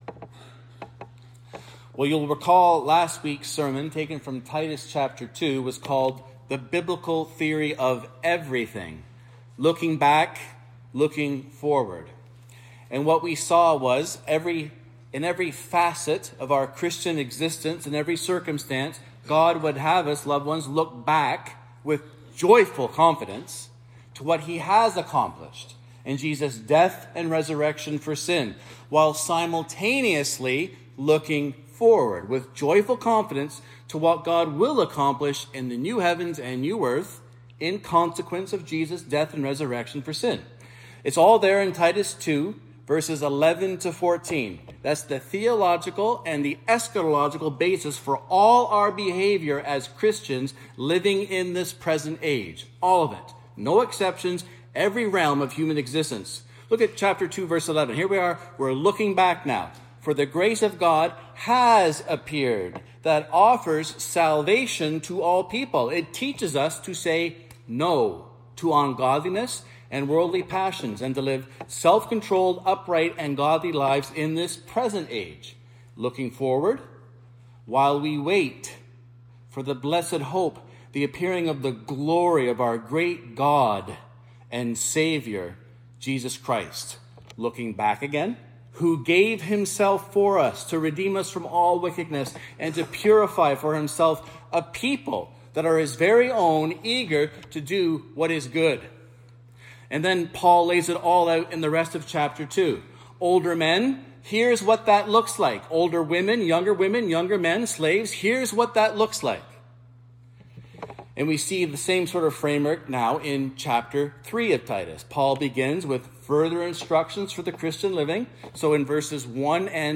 The sermons of Mount Pleasant Baptist Church in Toronto, Ontario.